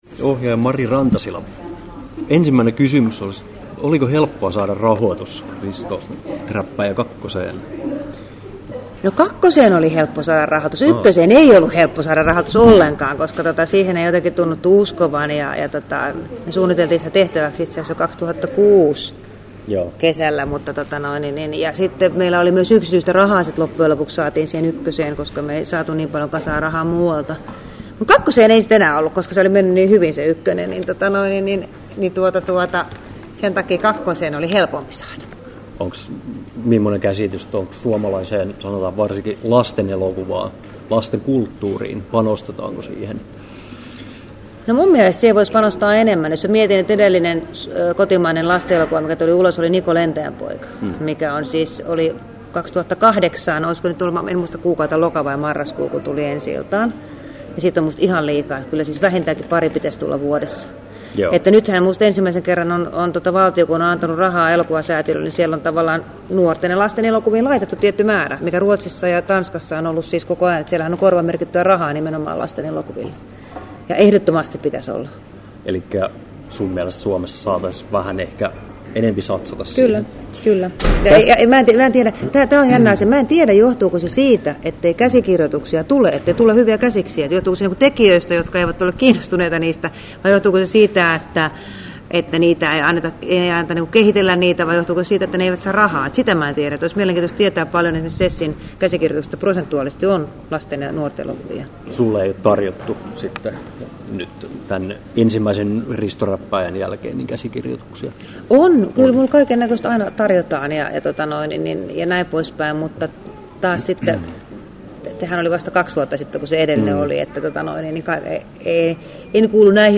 Mari Rantasilan haastattelu Kesto